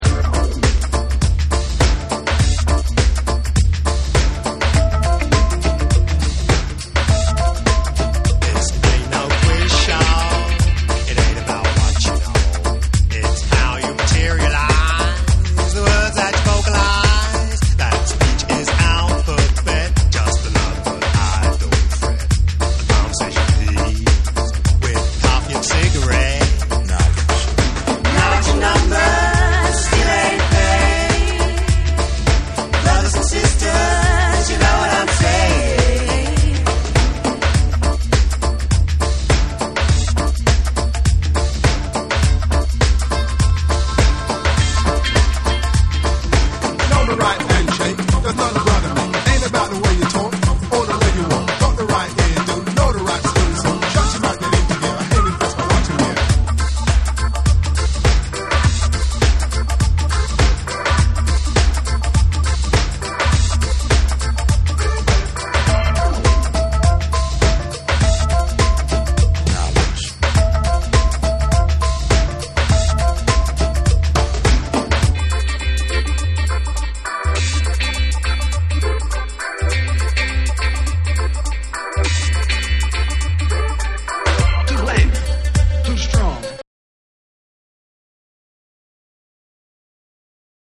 BREAKBEATS / CLUB